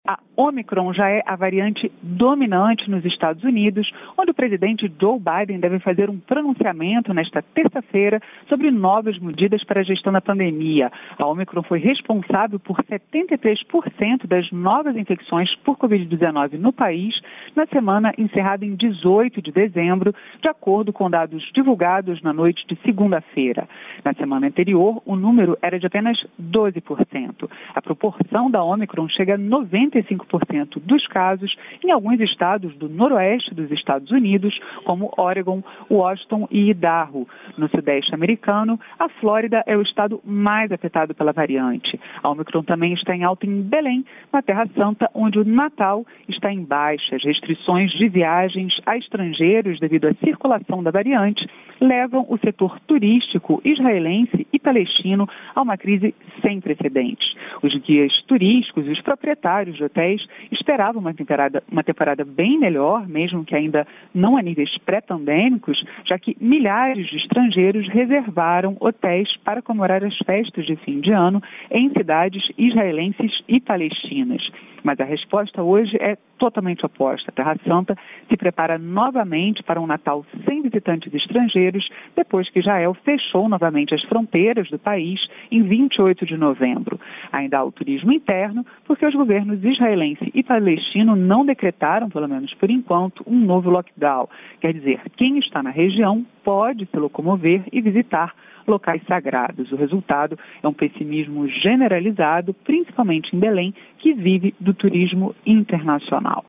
Paris